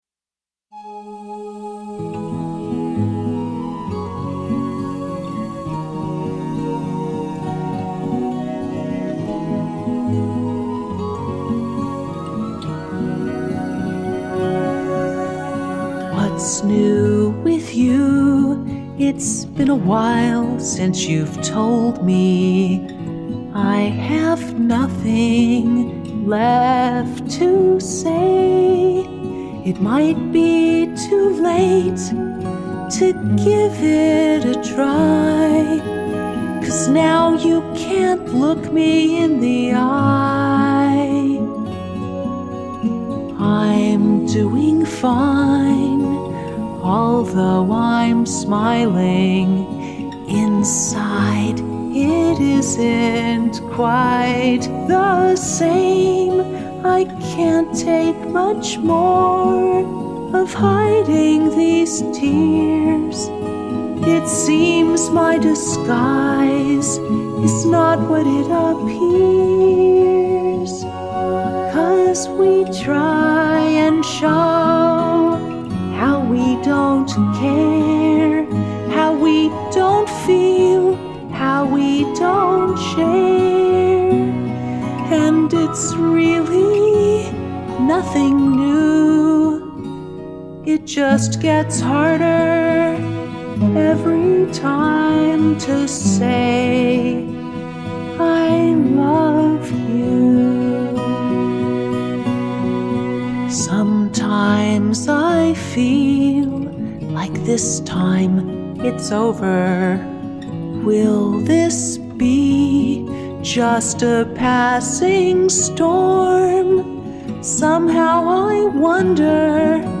And by the way, I still record many songs in my bathroom!